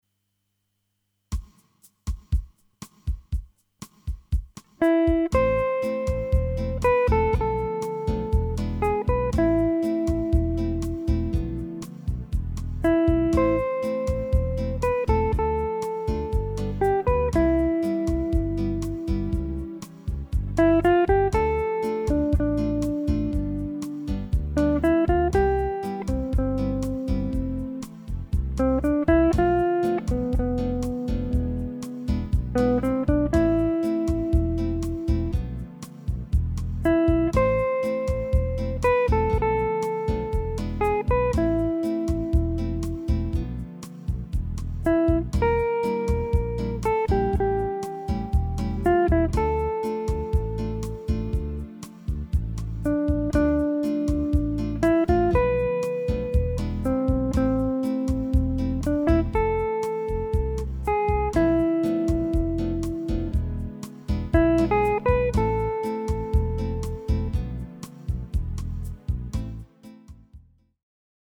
Voici un exemple de standard